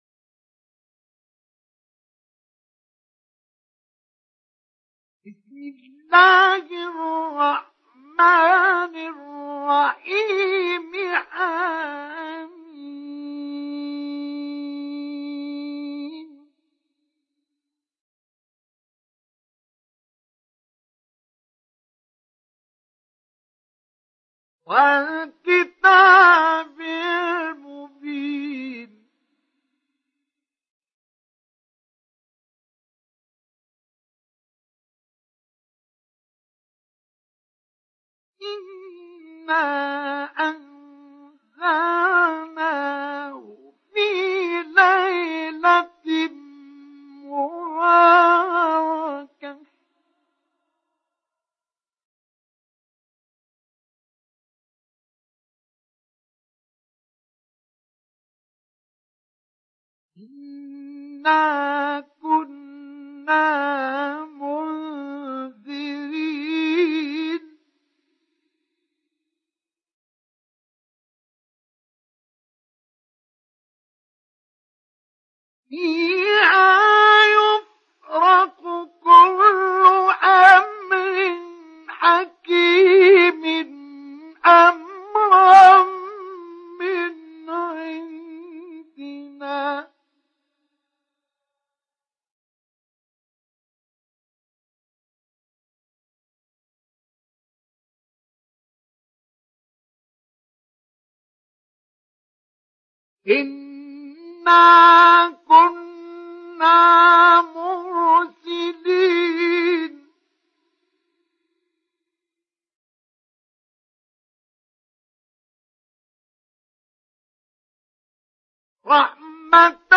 Sourate Ad Dukhan mp3 Télécharger Mustafa Ismail Mujawwad (Riwayat Hafs)
Télécharger Sourate Ad Dukhan Mustafa Ismail Mujawwad